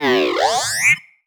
sci-fi_driod_robot_emote_20.wav